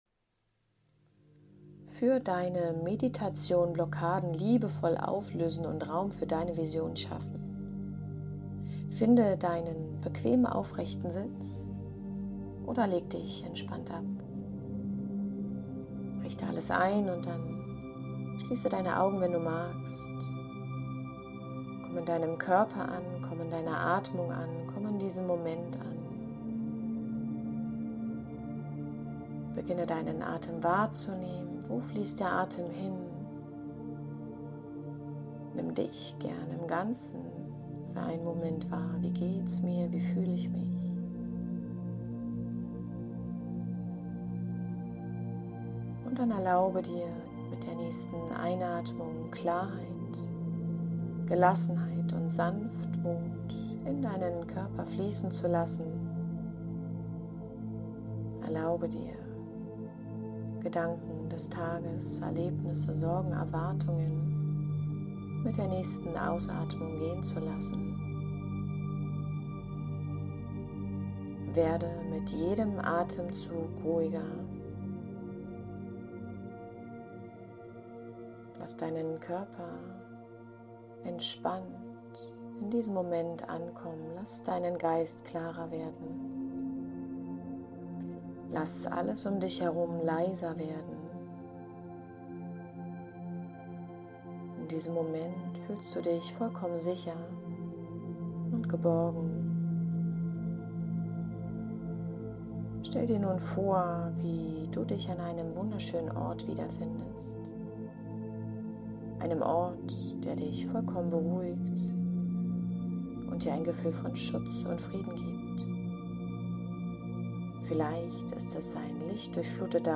Genieße deine Hörprobe!